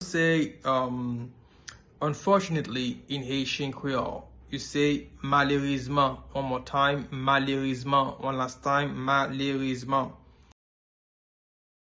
Listen to and watch “Malerezman” audio pronunciation in Haitian Creole by a native Haitian  in the video below:
30.How-to-say-Unfortunately-in-Haitian-Creole-–-Malerezman-pronunciation-.mp3